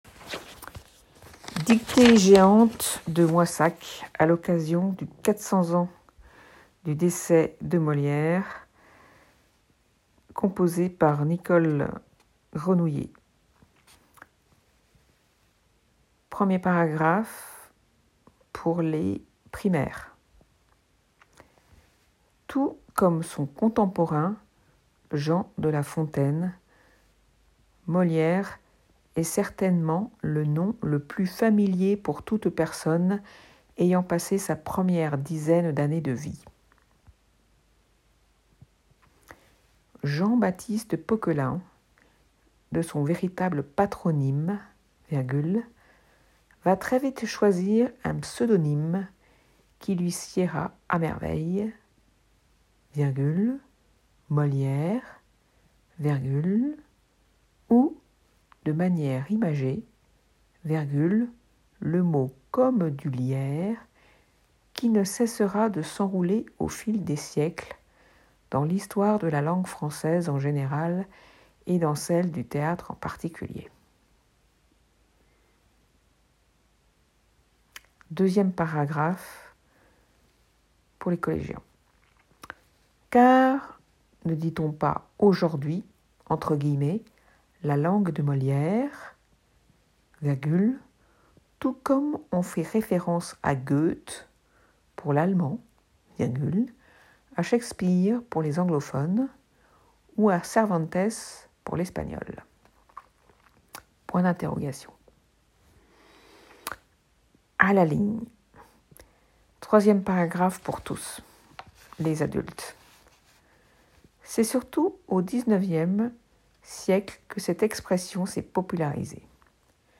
La deuxième édition de la dictée géante
Une introduction à la journée dédiée au XVIIème siècle qui se déroulait sur l’esplanade de l’Uvarium.